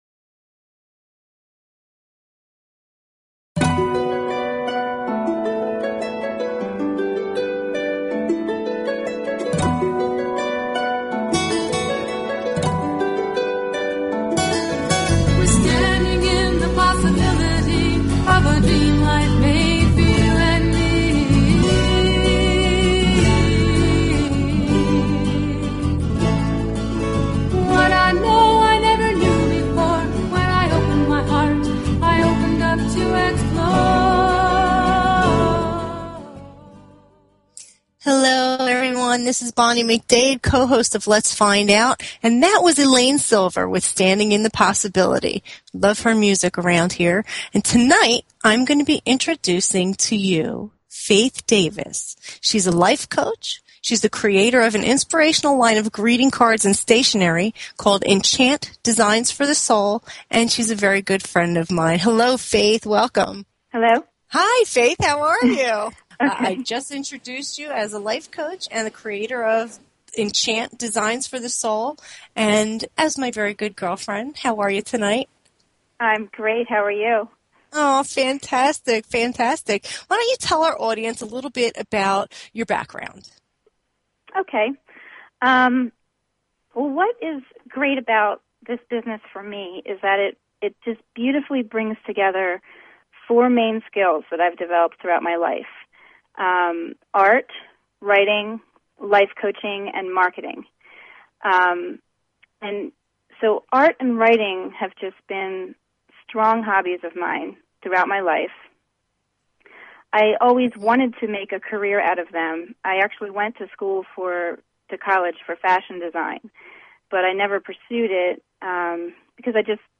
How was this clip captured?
The listener can call in to ask a question on the air.